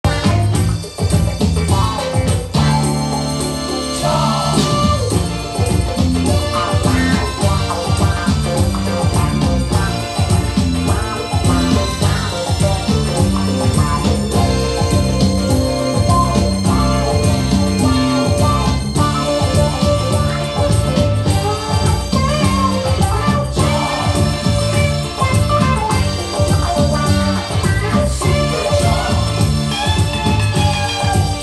Gênero: Funk, Soul
com a reprodução original do disco anunciado